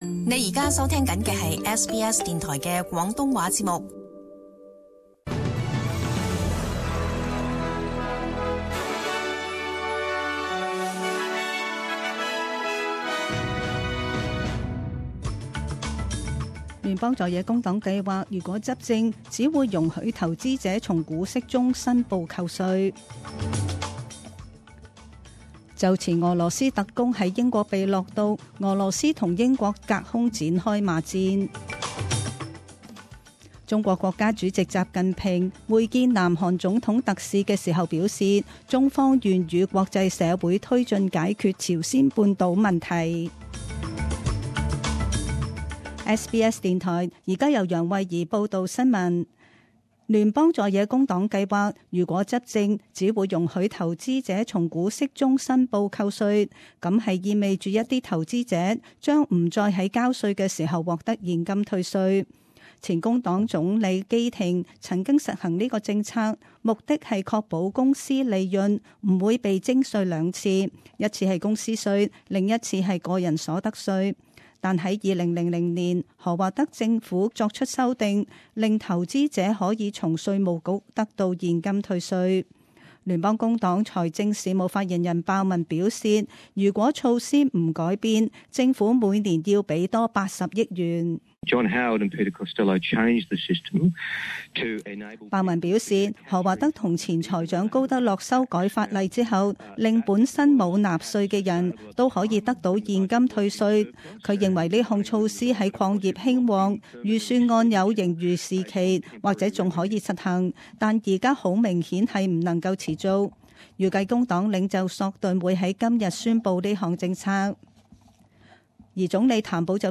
SBS中文新闻 （三月十三日）
请收听本台为大家准备的详尽早晨新闻。